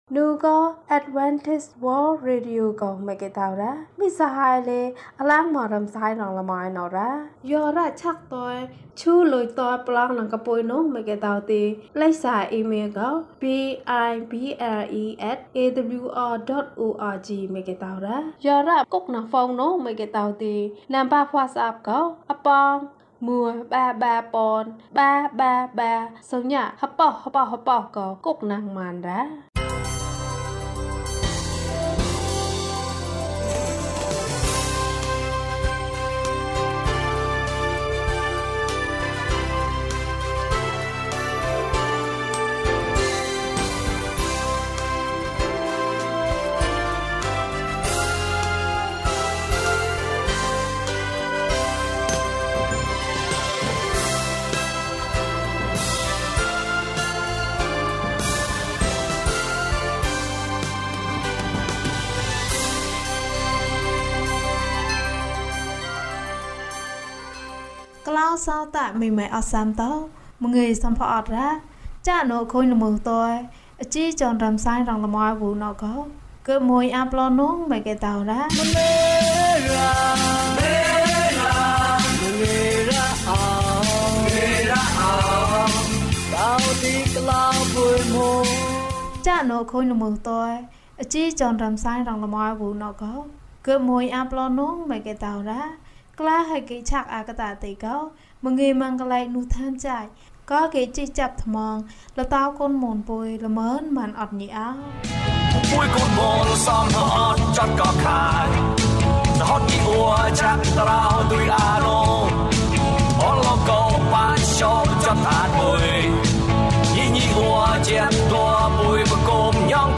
မြေကြီးပေါ်မှာ ဘုရားသခင် ဖန်ဆင်းတယ်။ ကျန်းမာခြင်းအကြောင်းအရာ။ ဓမ္မသီချင်း။ တရားဒေသနာ။